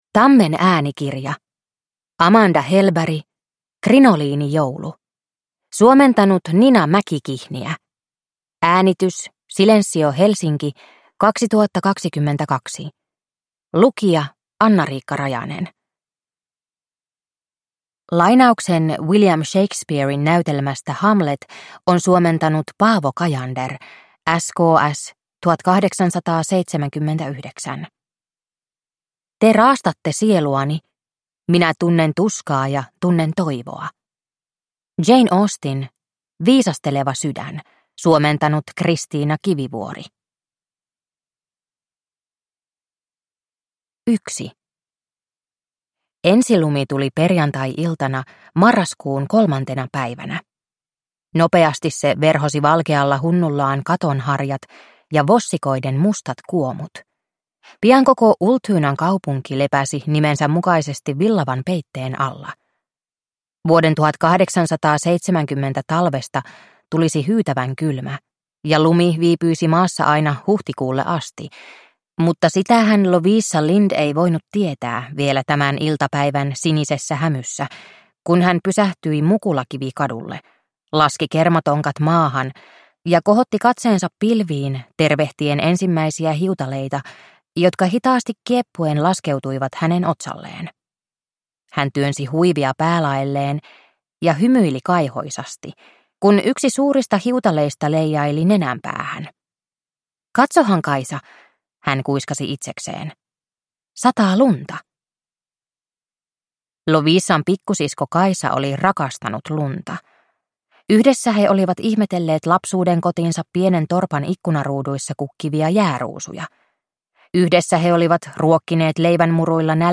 Krinoliinijoulu – Ljudbok – Laddas ner